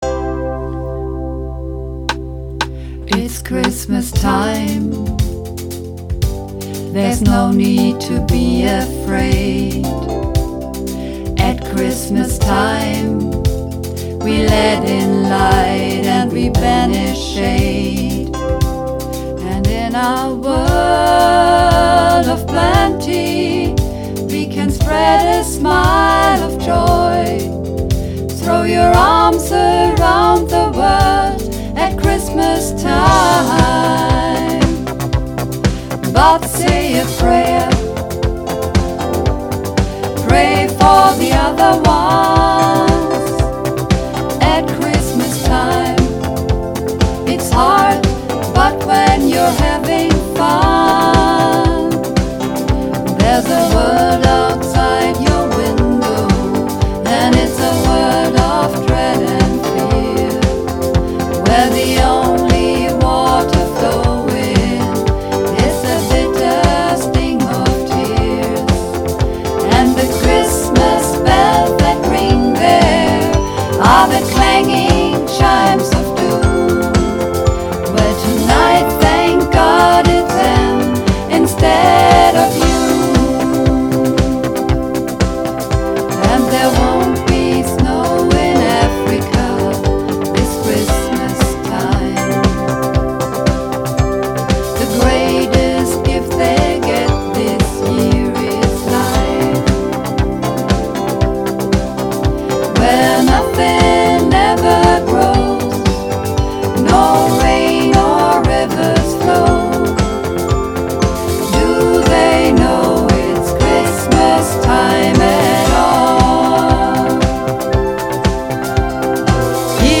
(Mehrstimmig)